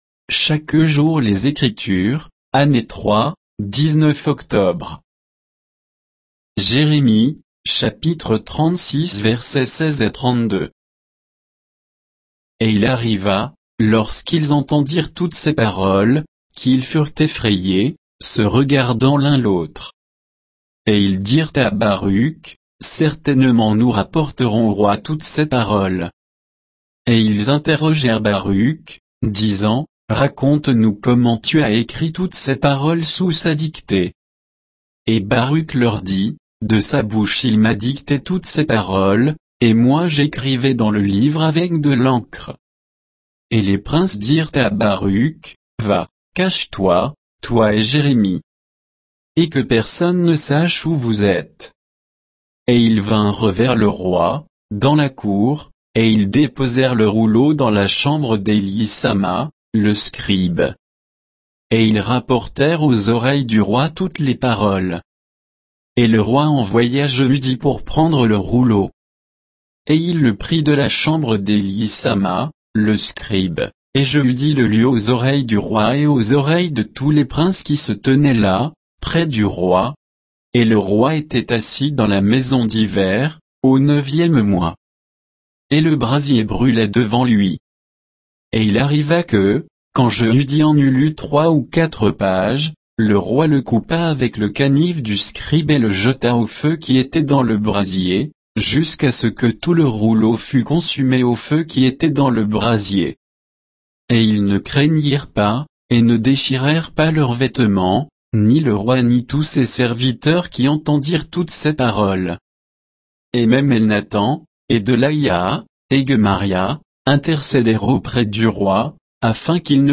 Méditation quoditienne de Chaque jour les Écritures sur Jérémie 36